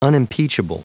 Prononciation du mot unimpeachable en anglais (fichier audio)
Prononciation du mot : unimpeachable